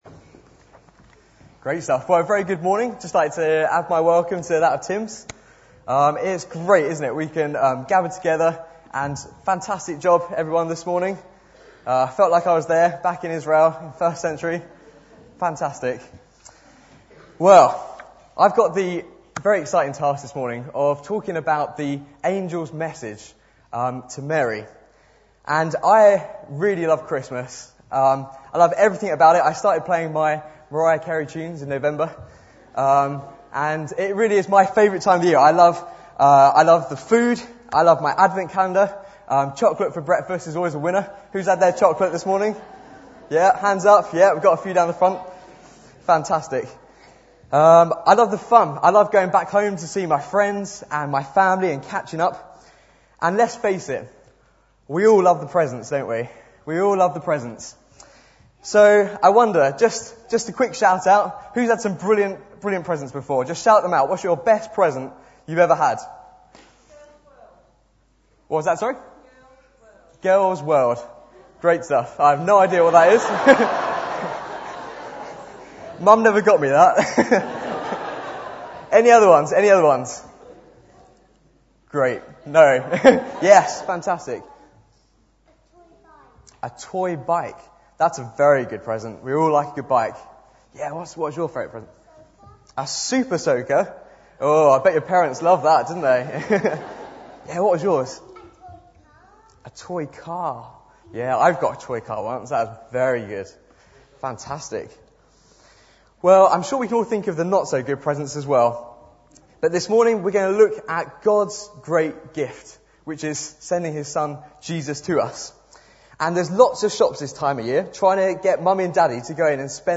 Bible Text: Luke 1:26-38 | Preacher